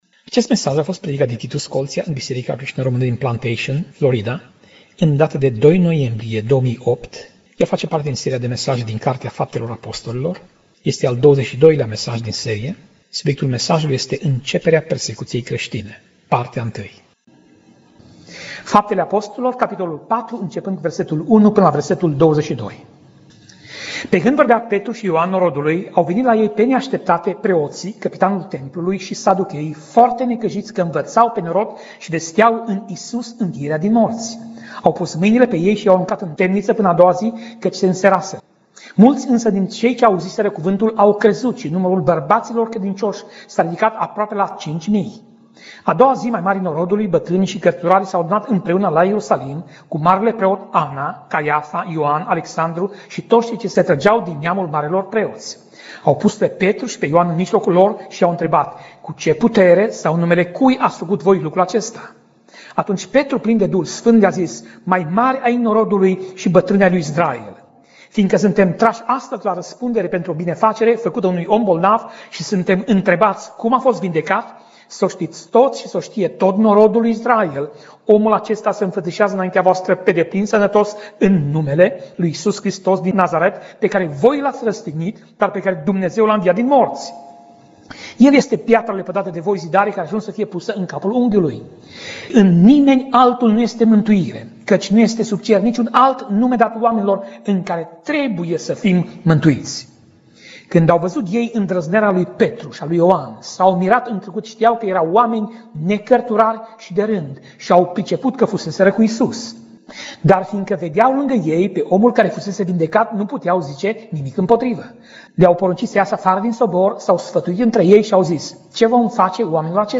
Pasaj Biblie: Faptele Apostolilor 4:1 - Faptele Apostolilor 4:20 Tip Mesaj: Predica